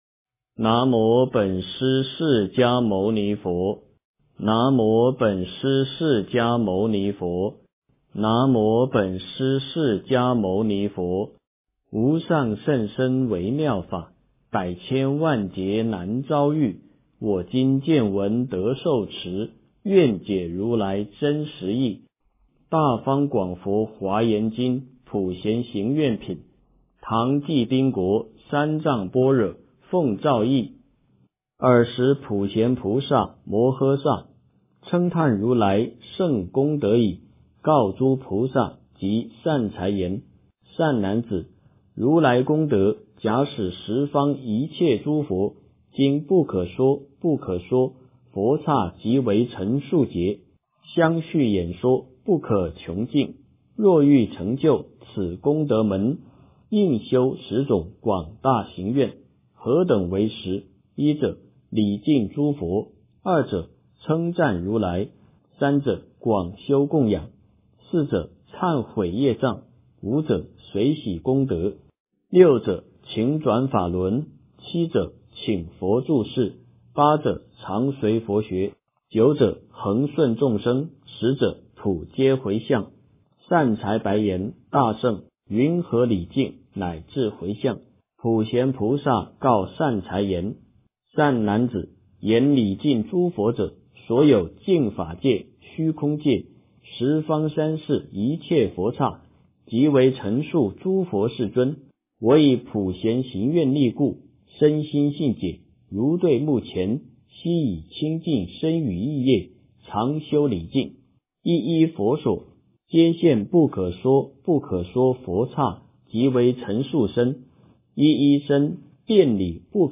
诵经
佛音 诵经 佛教音乐 返回列表 上一篇： 佛说阿弥陀经上 下一篇： 地藏经-较量布施功德缘品第十 相关文章 往生净土神咒 往生净土神咒--霹雳英雄...